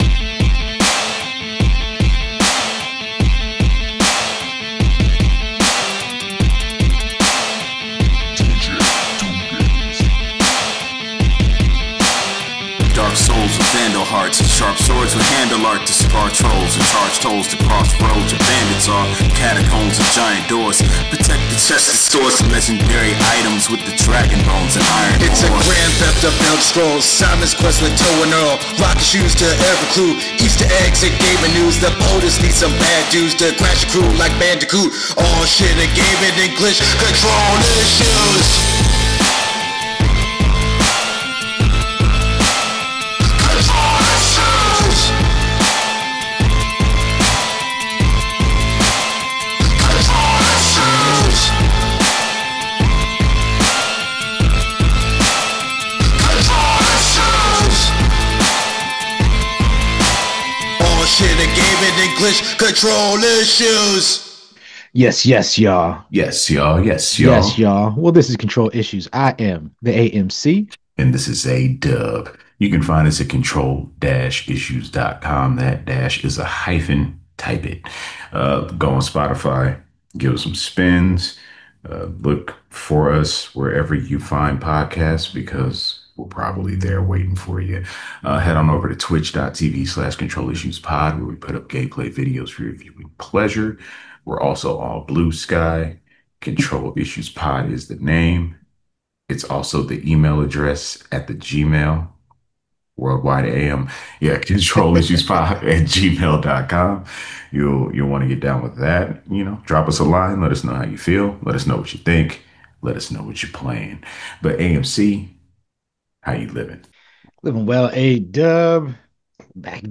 Three homies and a keyboard talking games, hunting trolls.